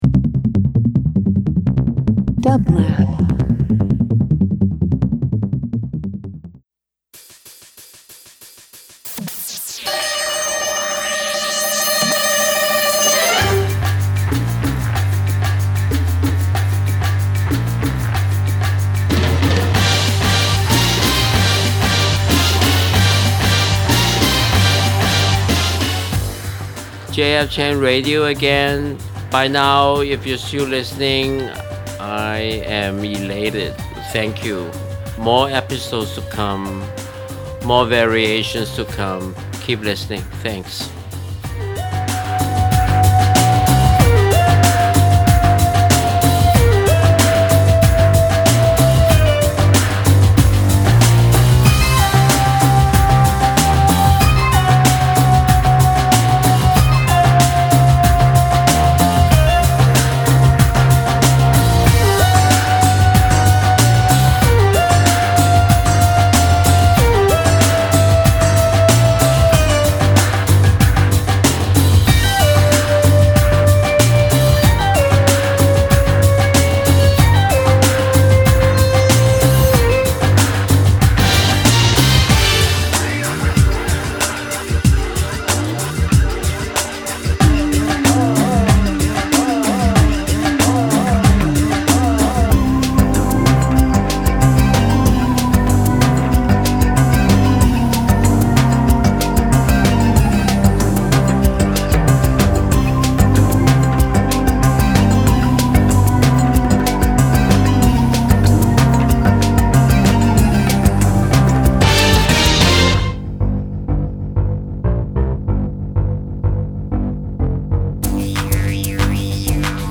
Alternative Country Electronic Jazz Pop Soundtracks